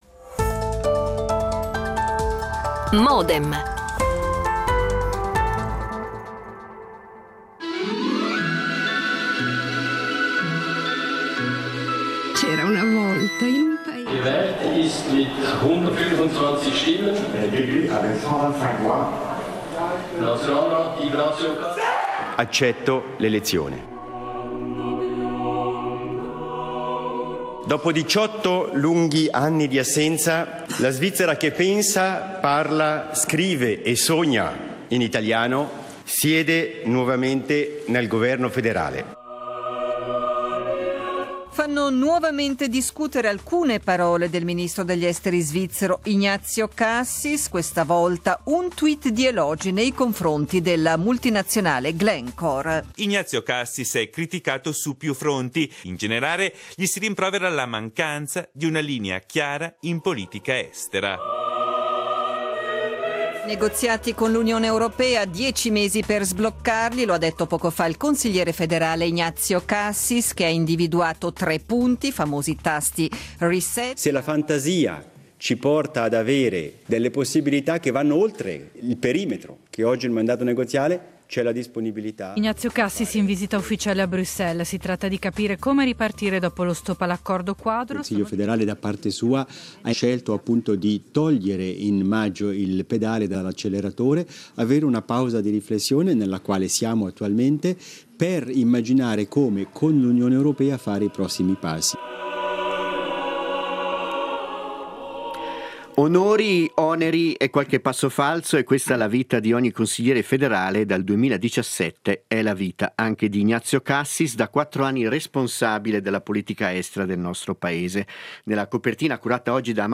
Due gli ospiti di questa puntata: Alex Farinelli, consigliere nazionale PLR/TI Fabio Regazzi, consigliere nazionale PPD/TI E un intervento registrato di Carlo Sommaruga, consigliere agli Stati PS/GE
L'attualità approfondita, in diretta, tutte le mattine, da lunedì a venerdì